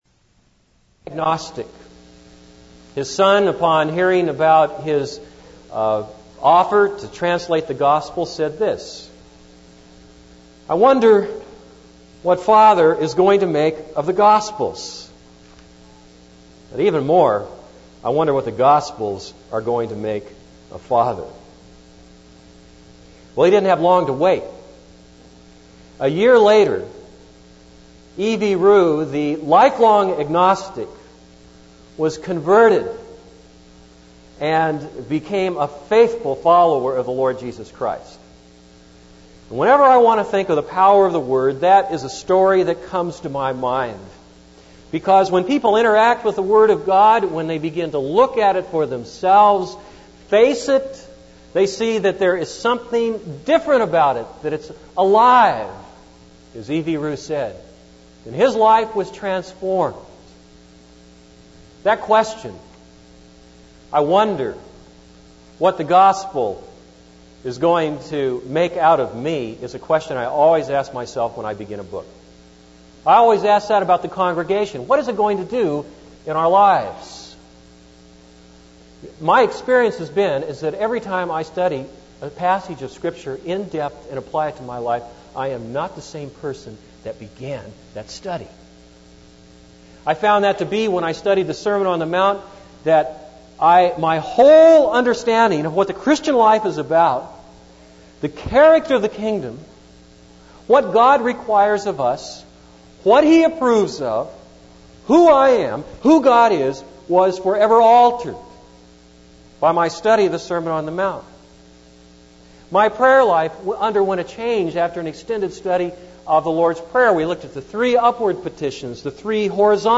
This is a sermon on Mark 10:45.